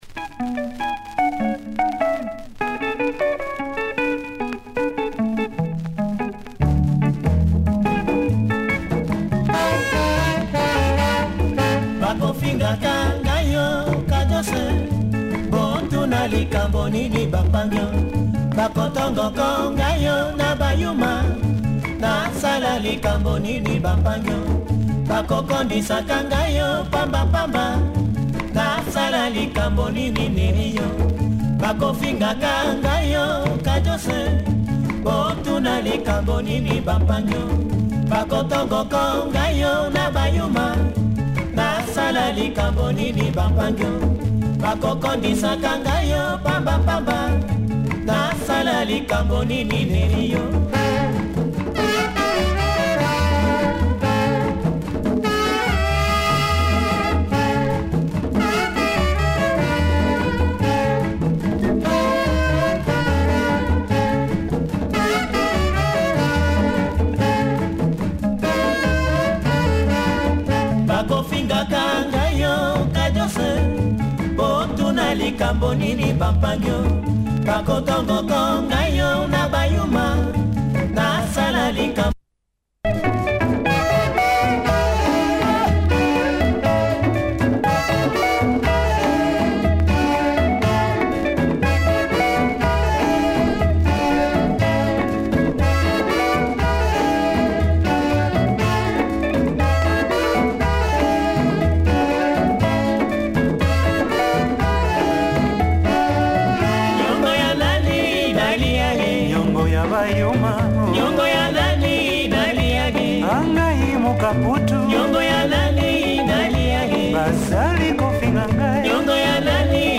full orchestra style